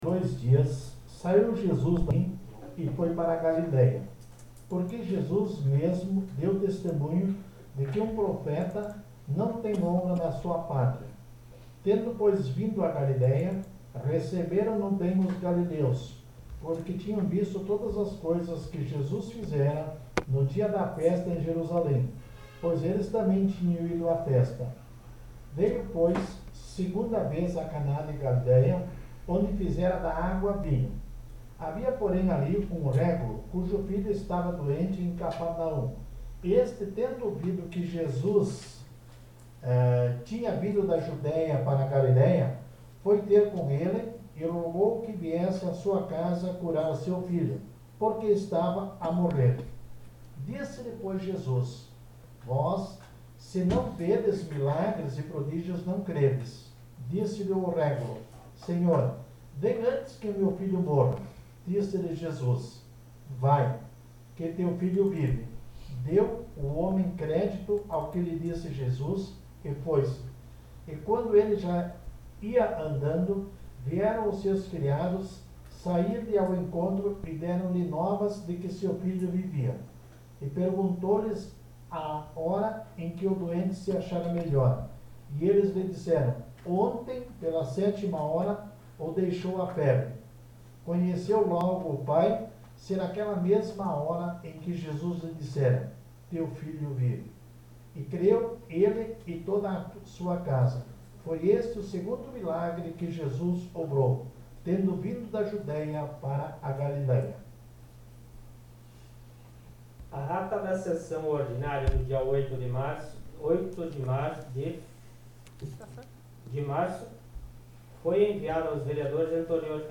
Sessão Ordinária do dia 15 de março de 2021 — Câmara Municipal de Barão - RS